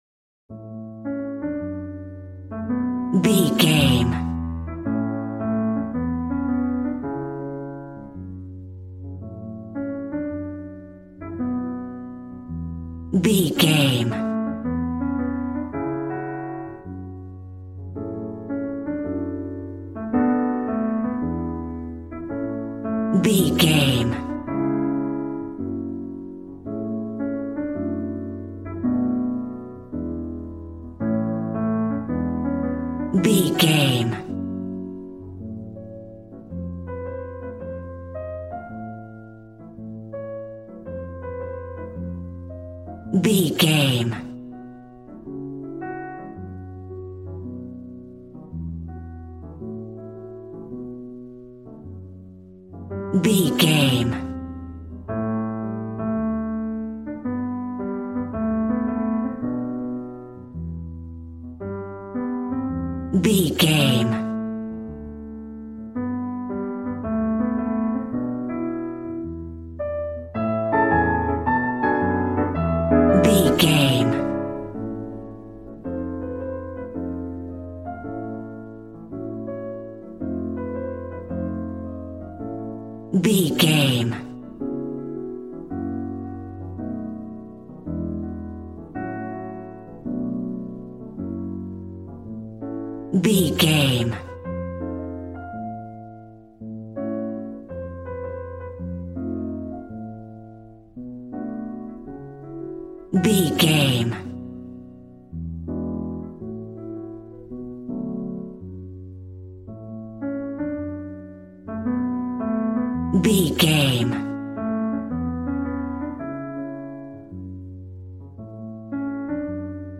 Smooth jazz piano mixed with jazz bass and cool jazz drums.,
Ionian/Major